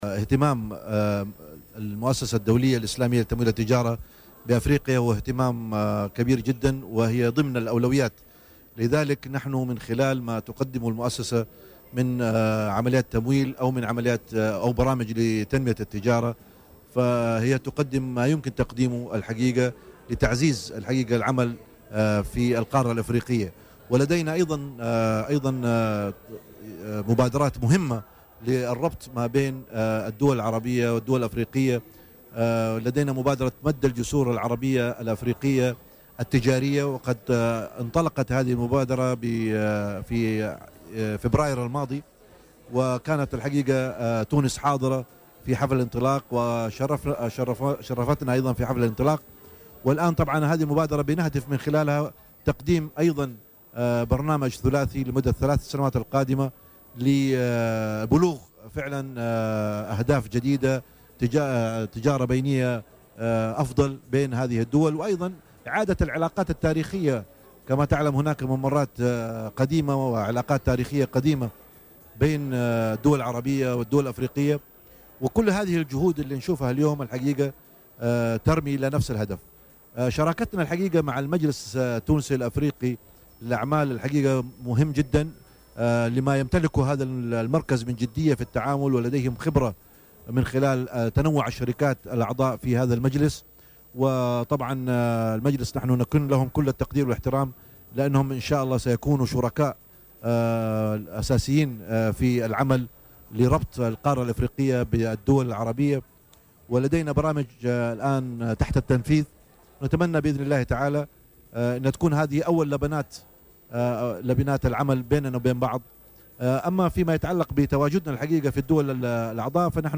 وأضاف خلال مداخلة له اليوم في برنامج "بوليتيكا" على هامش انعقاد اشغال منتدى تمويل الاستثمار والتجارة الخارجية في افريقيا، أن الهدف من هذه المبادرة هو تعزيز فرص الاستثمار المتاحة بالأسواق العربية والإفريقية والرفع من حجم المبادلات البينية وإعادة العلاقات التاريخية بين هذه الدول ومزيد تنويع برامج التعاون فيما بينها.